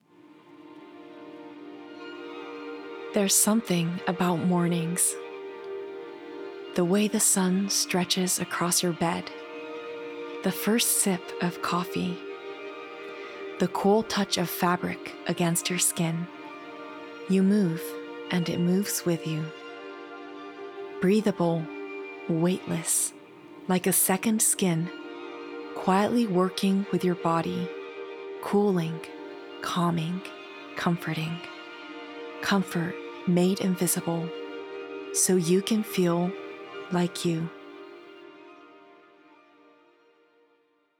– ナレーション –
ささやき・ウィスパー